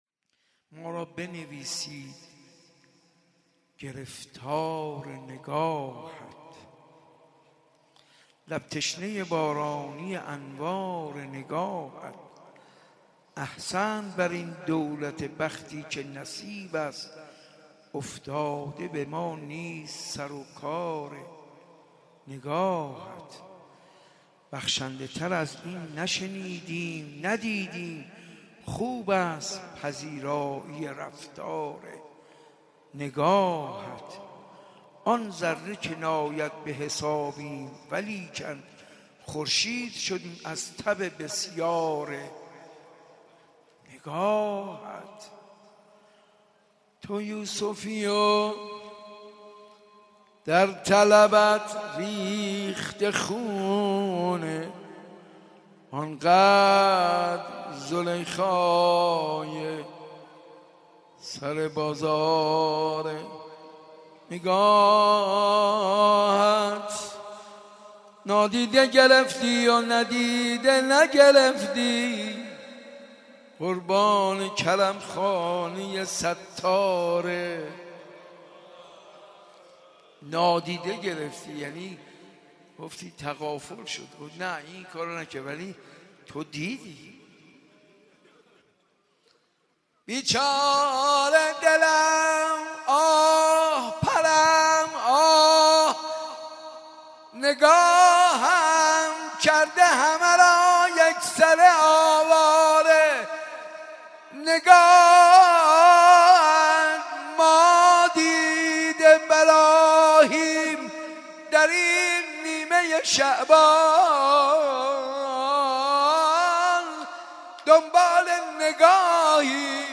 میلاد امام زمان (عج) 95 - روضه - ما را بنویسید گرفتار نگاهت